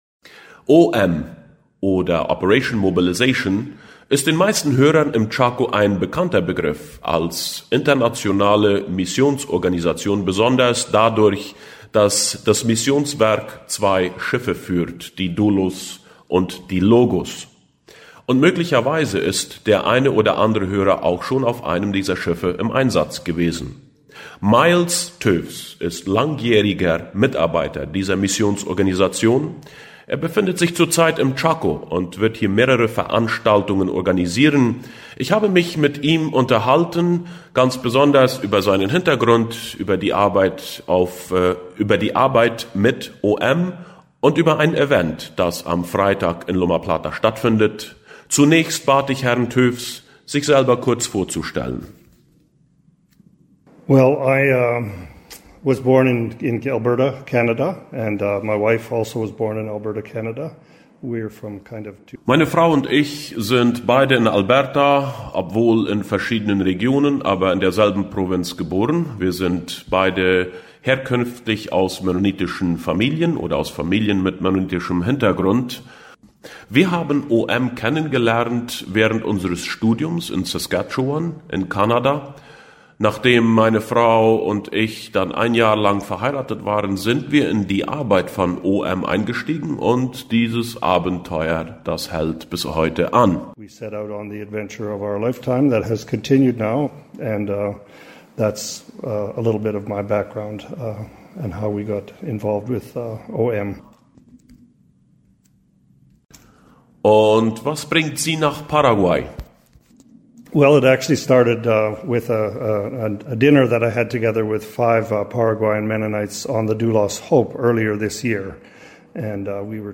Vortrag fuer Unternehmer